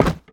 Minecraft Version Minecraft Version snapshot Latest Release | Latest Snapshot snapshot / assets / minecraft / sounds / entity / armorstand / hit2.ogg Compare With Compare With Latest Release | Latest Snapshot